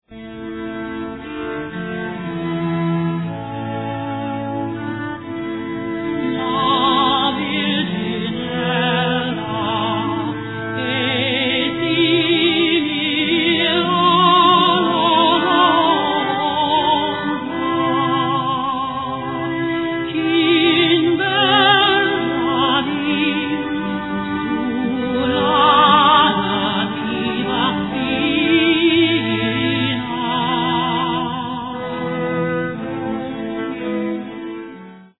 A richly authentic performance on original instruments
countertenor
Recorded in London.